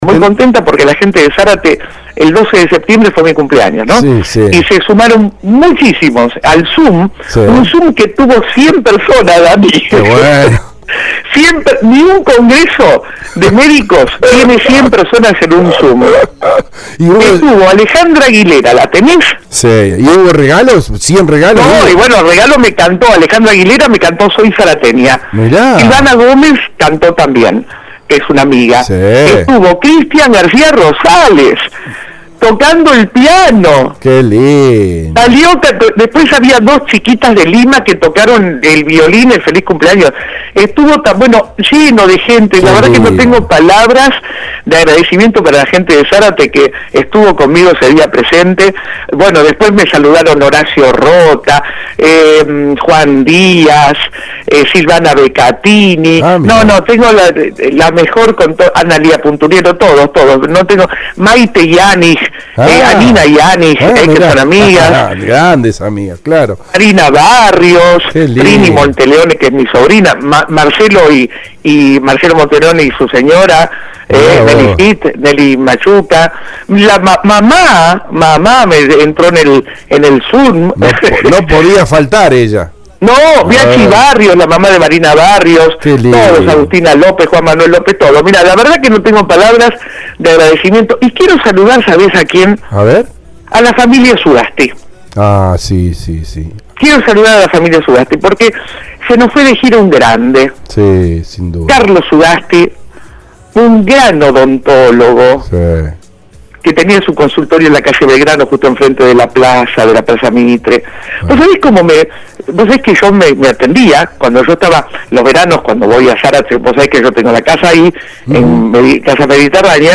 LOS IMPERDIBLES AUDIOS DE LA ENTREVISTA